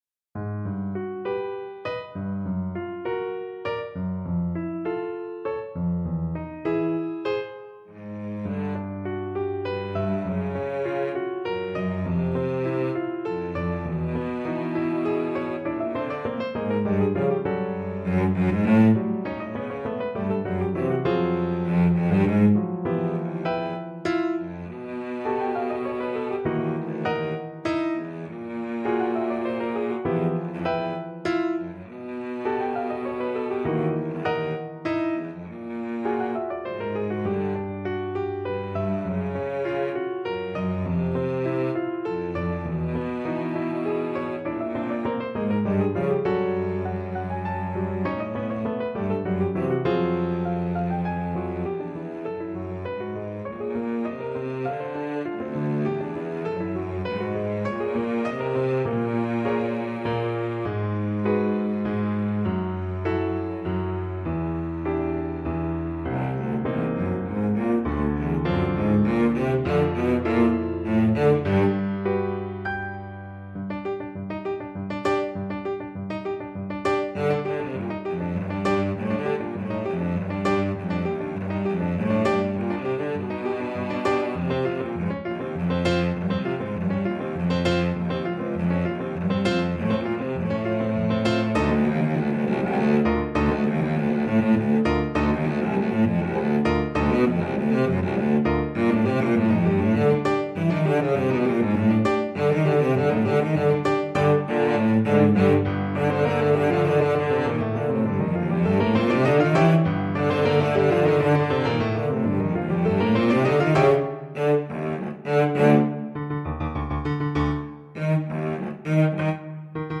Contrebasse et Piano